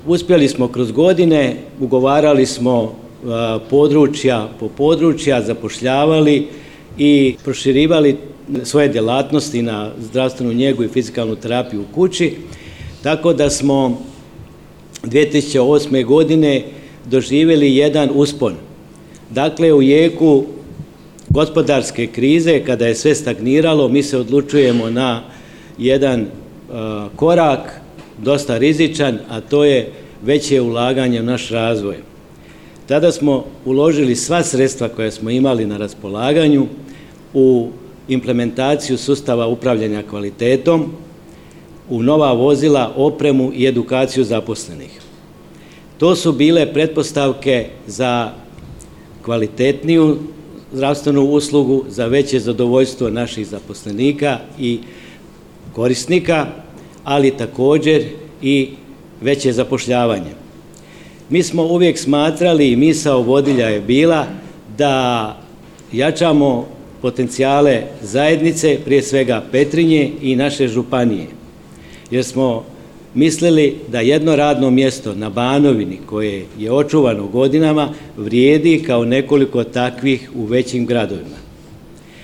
Prigodnom svečanosti u Sisku, pod pokroviteljstvom Sisačko-moslavačke županije i Hrvatske komore medicinskih sestara, zdravstvena ustanova Zrinus iz Petrinje obilježila je 20 godina rada.